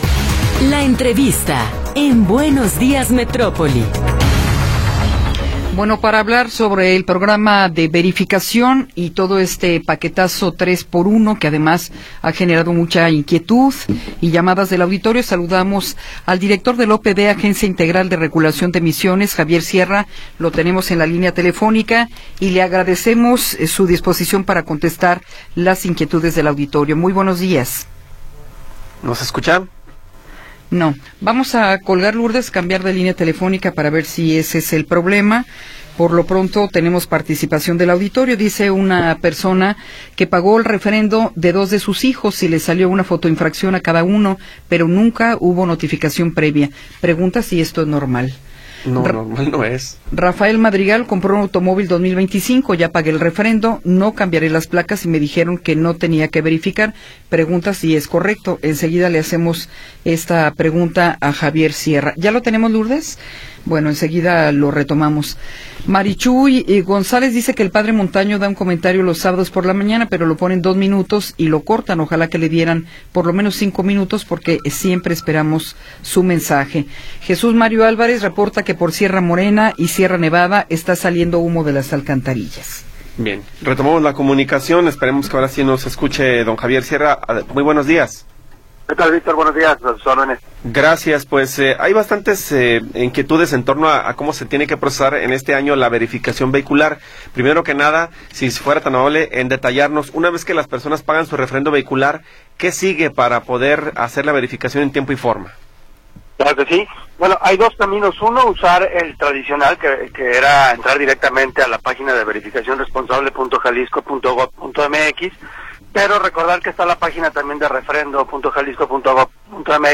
Entrevista con Javier Sierra Moreno